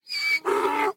mob / horse / donkey / angry2.ogg
angry2.ogg